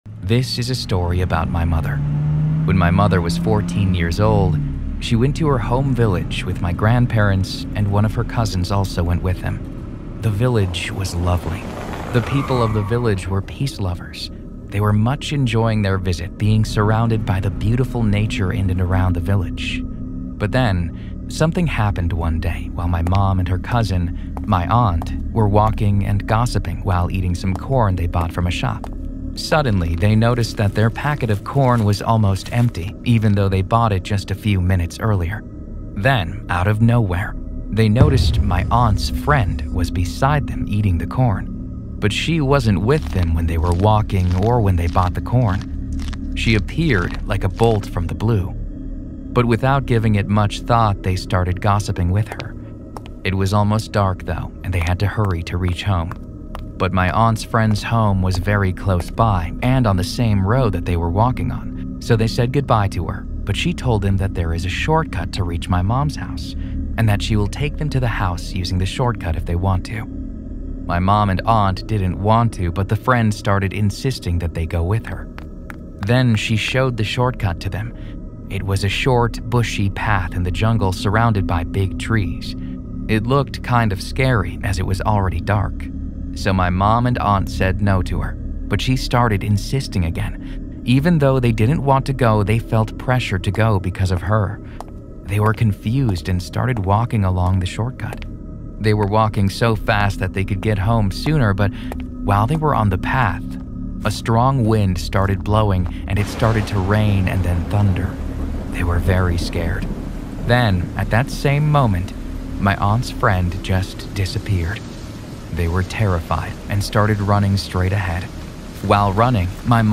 Before the story begins, Scary Stories wants you to know something important: all advertisements are placed at the very beginning of each episode so nothing interrupts the experience once the darkness settles in.